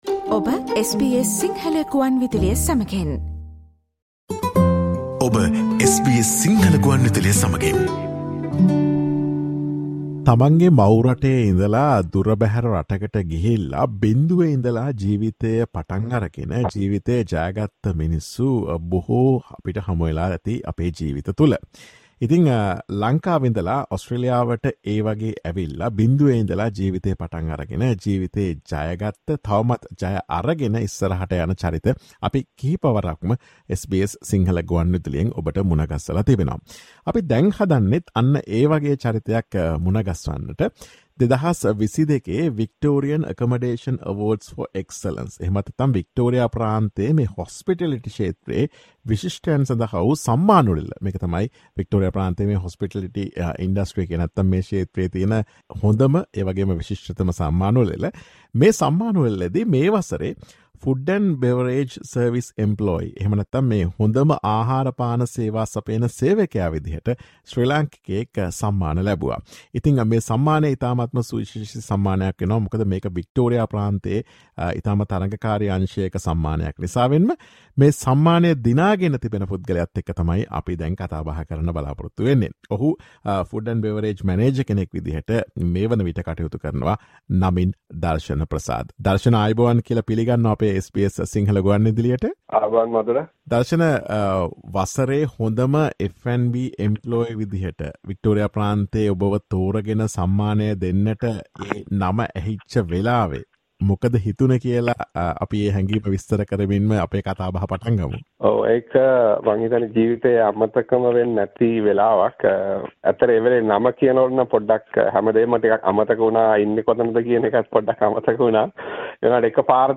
Listen to the SBS Sinhala Radio's interview with him about his story.